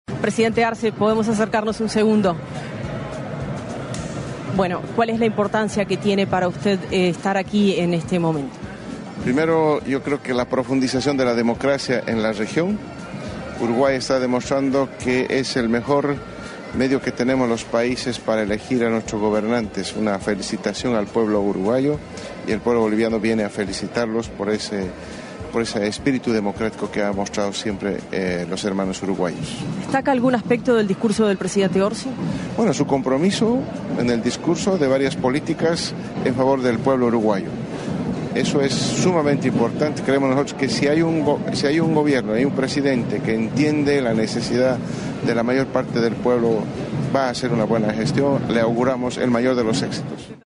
Palabras del presidente de Bolivia, Luis Arce
Este sábado 1.° de marzo, en oportunidad del traspaso de mando presidencial en Uruguay, se expresó el mandatario del Estado Plurinacional de Bolivia,